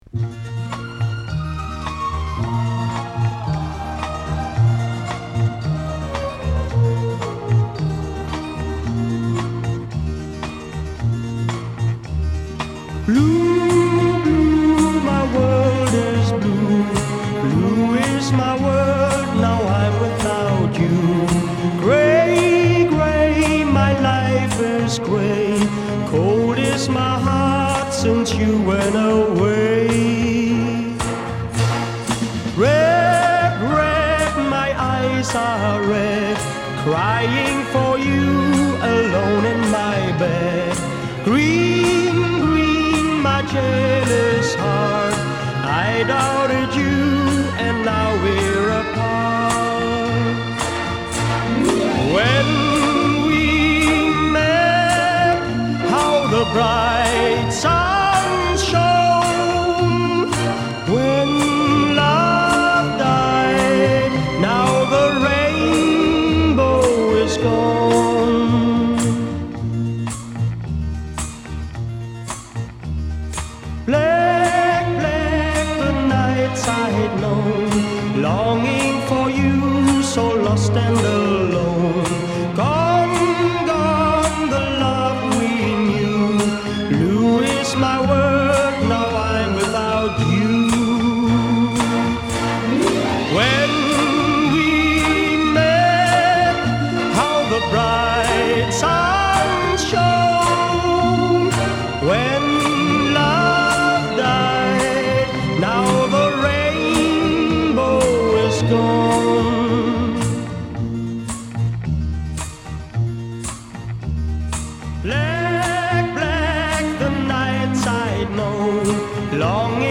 Genre: Easy Listening,Psychedelic,Instrumental